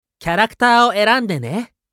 男性
☆★☆★システム音声☆★☆★